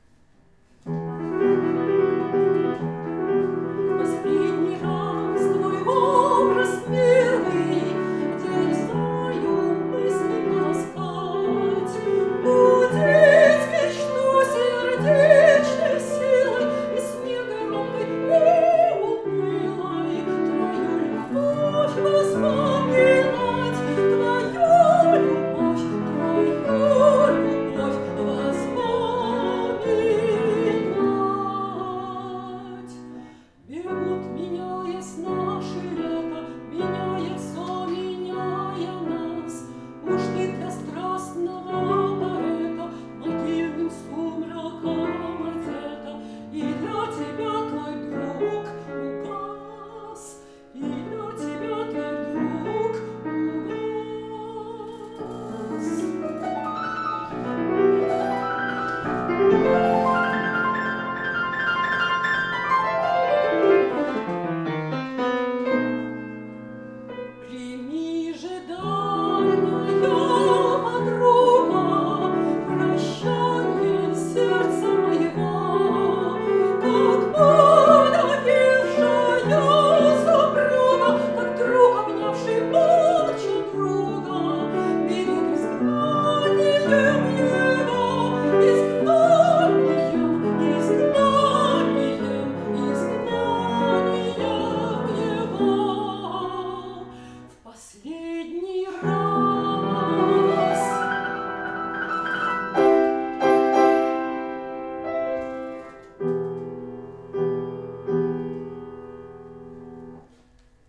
«Послания музам» вокальный цикл на стихи А. Пушкина
Партия фортепиано
Малый концертный зал Колледжа искусств